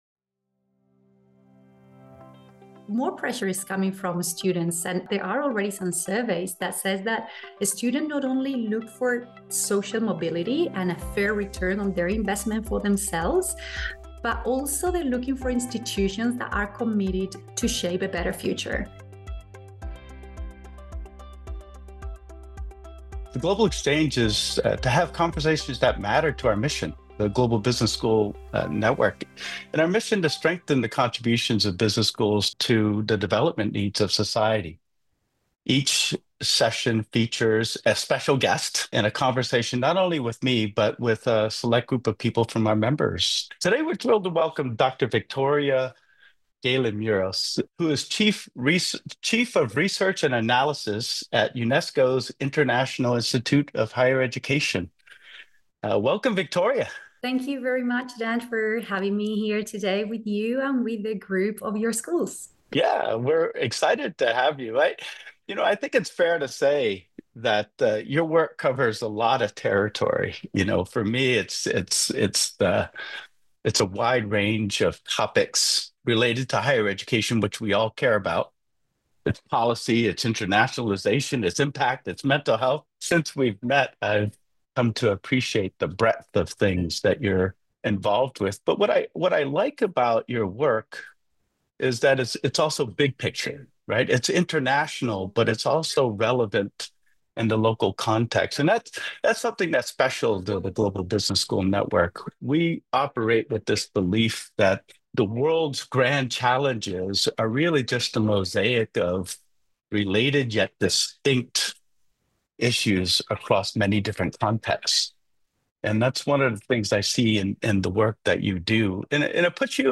The Global Exchange: A Conversation